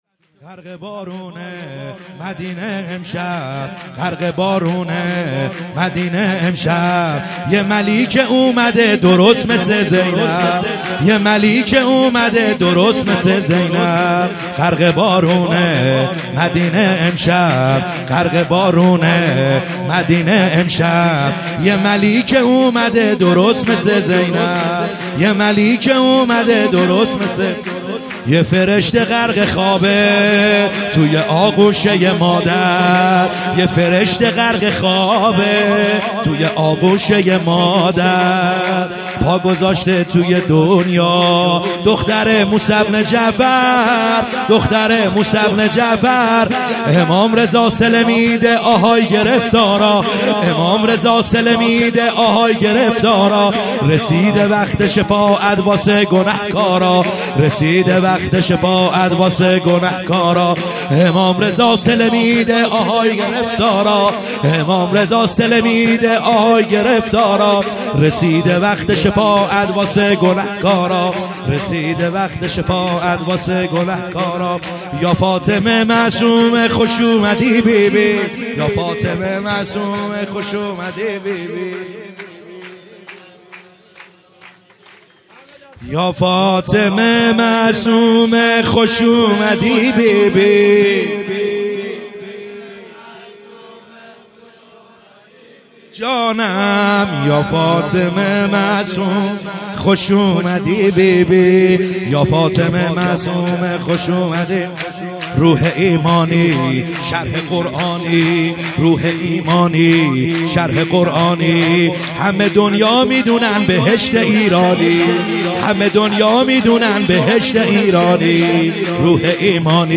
خیمه گاه - بیرق معظم محبین حضرت صاحب الزمان(عج) - سرود | غرق بارون مدینه امشب